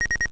These audio samples were taken off of a real Atari 1200XL using a Pentium.
The computer response to your keyboard commands.